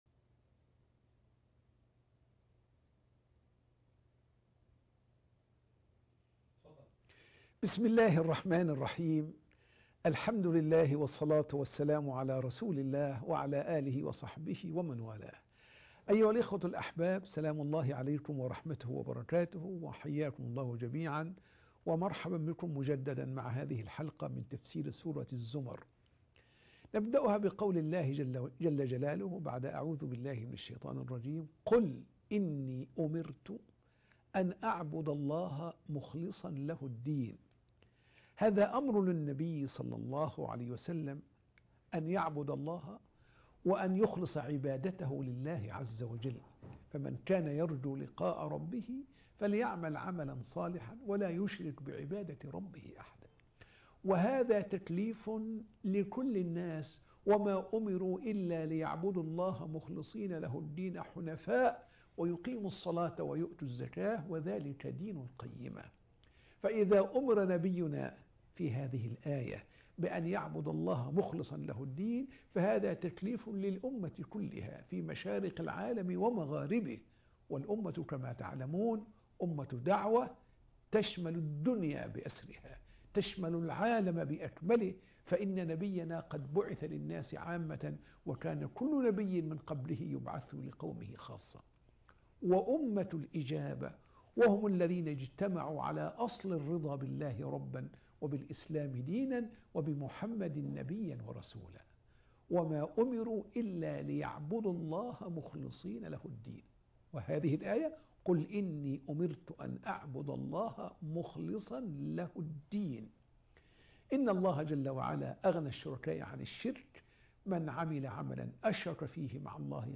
(2) تدبرات في سورة الزمر - بث مباشر